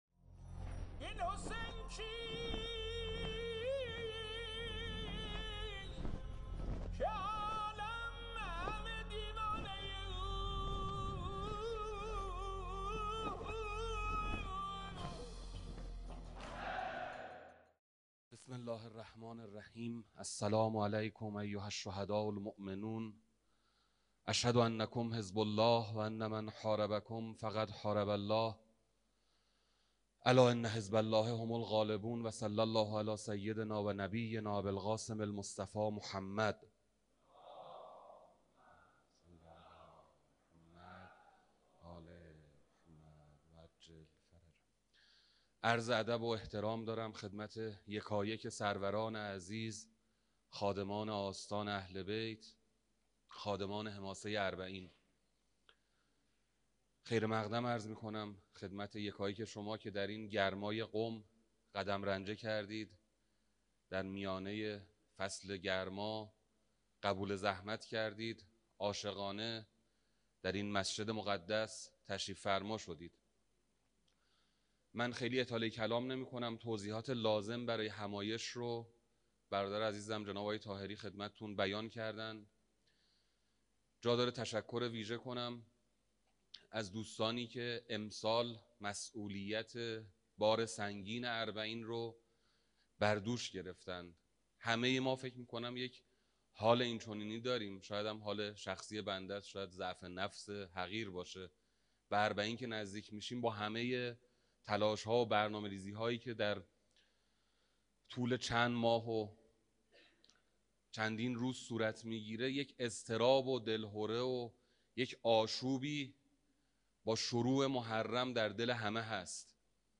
چهارمین همایش ملی هیأت های فعال در عرصه اربعین